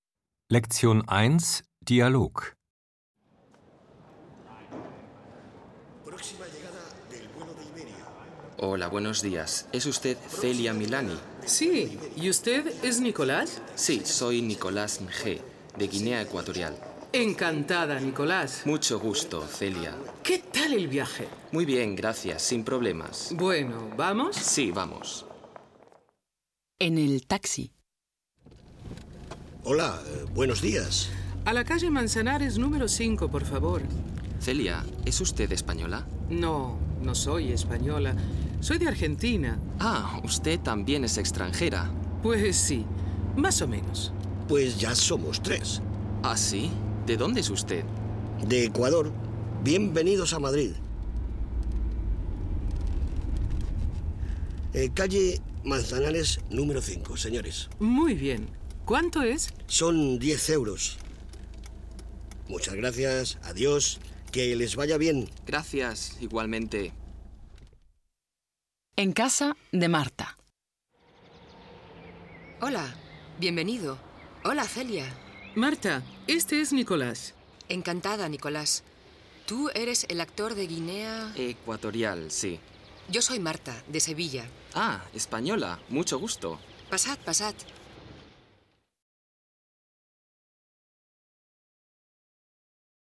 4 Audio-CDs mit Dialogen in zwei Sprechgeschwindigkeiten und abwechslungsreichem Hör- und Sprechtraining